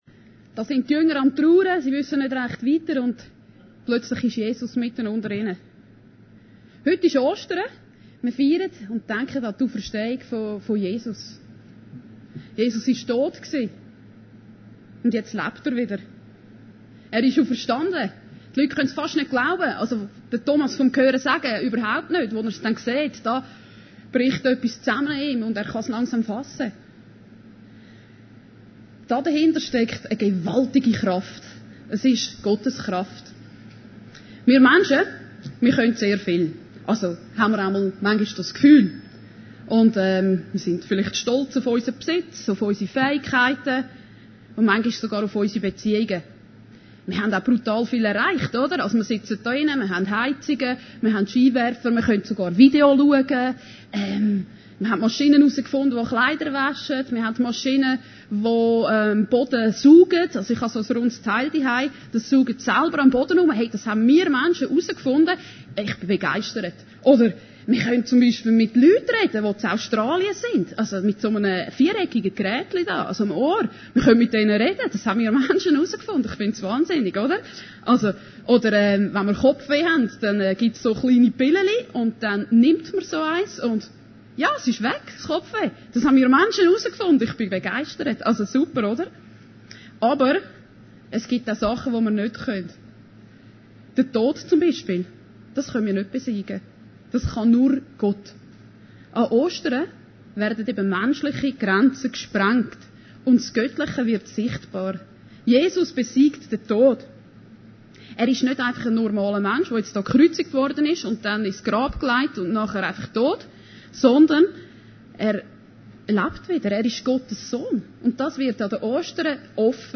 Predigten Heilsarmee Aargau Süd – leben mit der auferstehungskraft